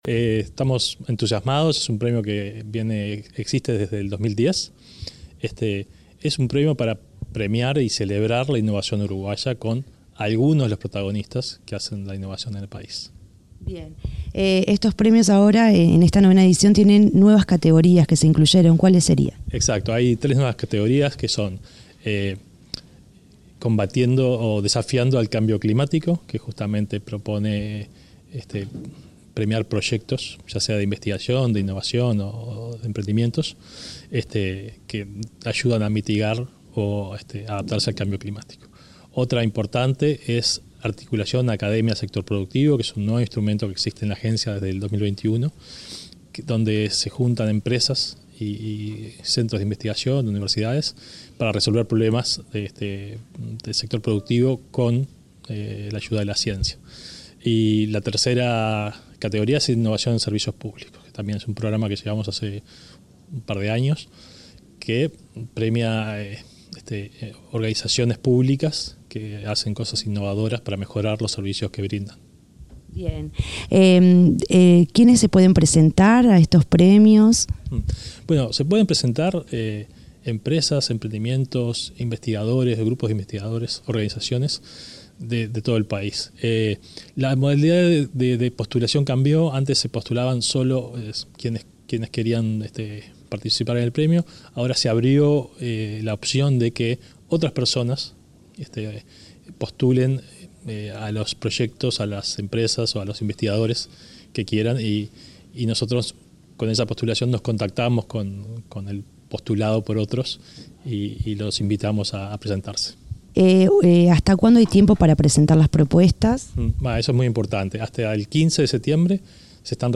Entrevista al presidente de ANII, Flavio Caiafa